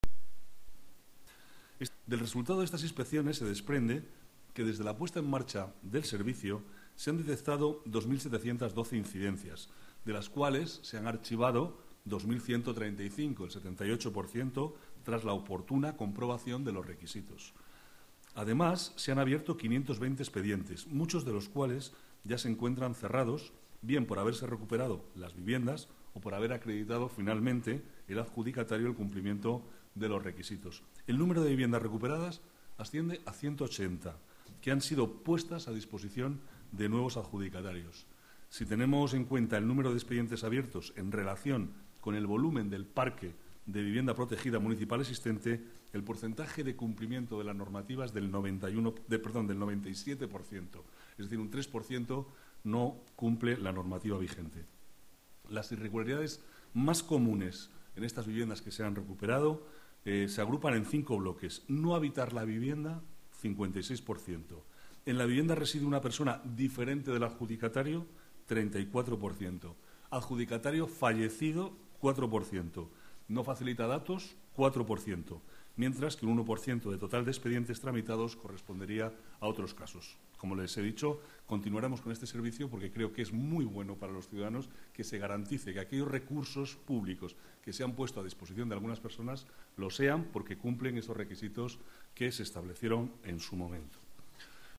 Nueva ventana:Declaraciones del vicealcalde, Manuel Cobo, sobre las inspecciones inmobiliarias